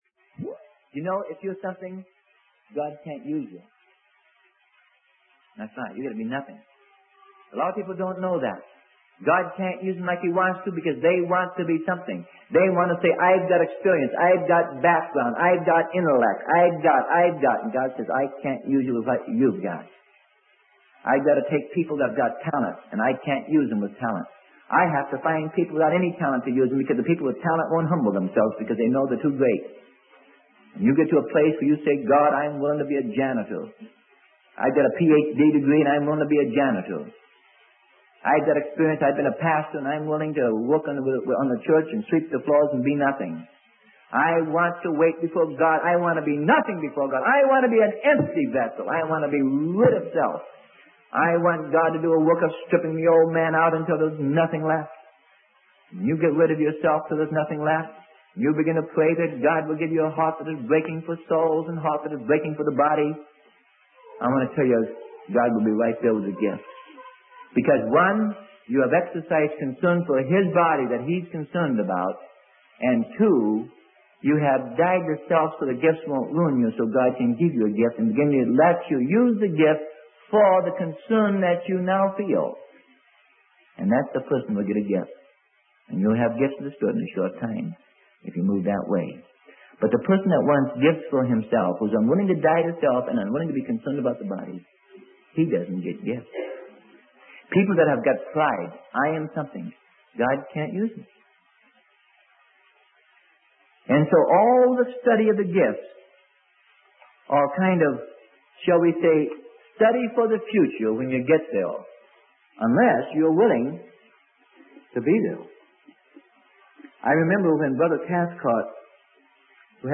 Sermon: Gifts of the Spirit - Part 4 - Freely Given Online Library